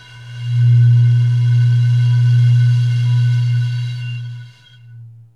WINE GLAS00L.wav